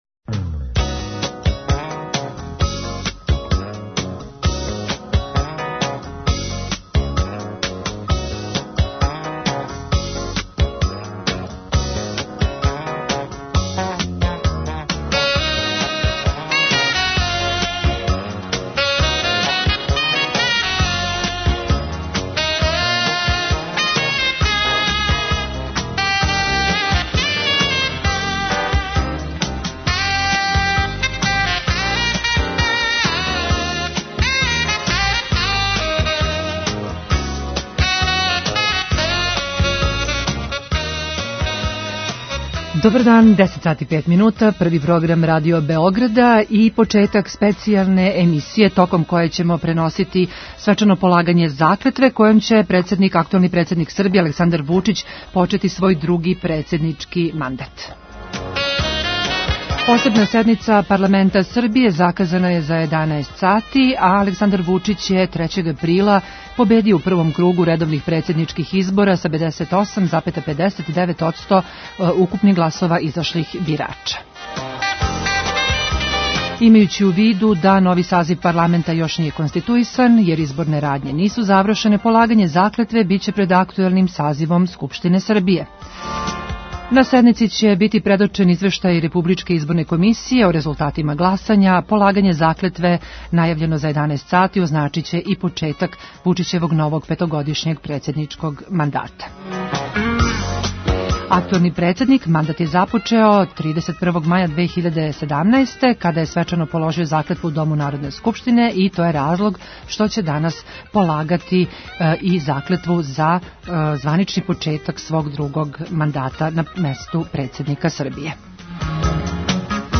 Наши репортери ће бити испред и у Скупштини Србије као и у Председништву.
Током емисије слушаоци ће моћи да у директном преносу прате чин полагања заклетве председника Вучић.